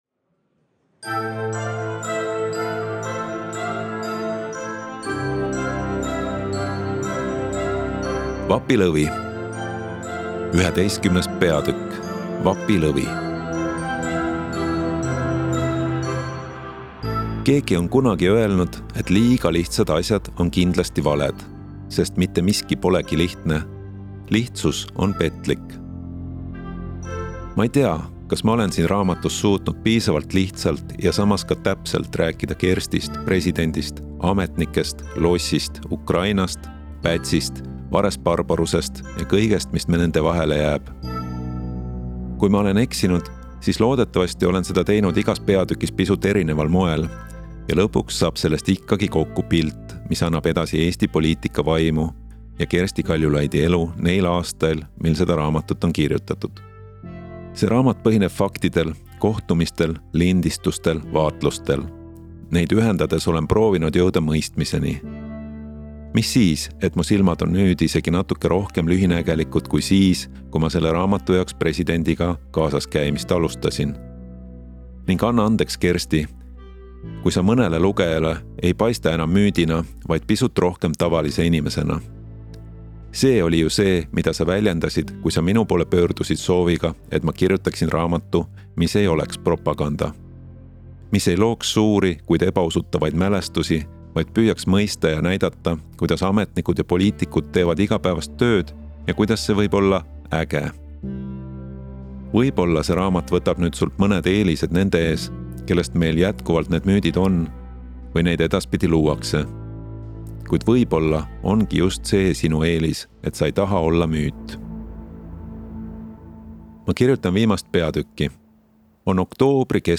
Osta kõik peatükid audioraamat e-raamat 11,99 € Telli raamat audioraamat e-raamat paberraamat Järgmine lugu